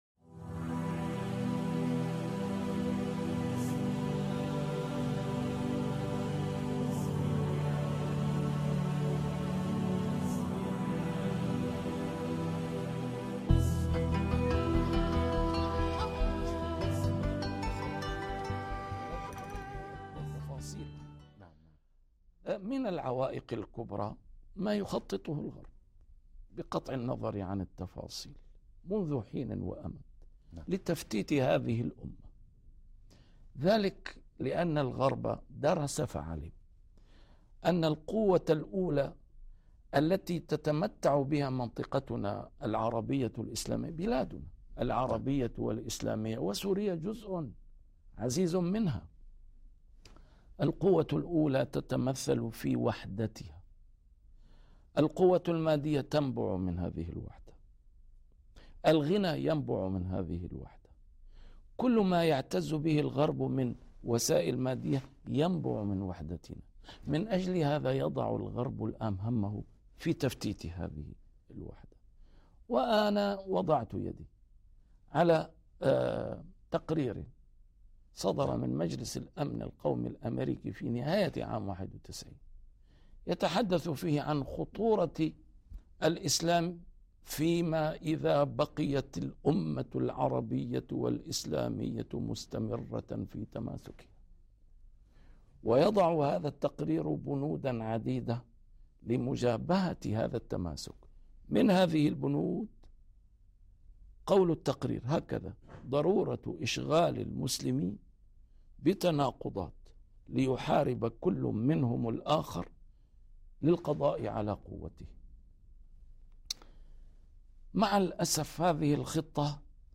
A MARTYR SCHOLAR: IMAM MUHAMMAD SAEED RAMADAN AL-BOUTI - الدروس العلمية - محاضرات متفرقة في مناسبات مختلفة - ندوة تلفزيونية بعنوان : الوحدة أقدس ما جاء به الإسلام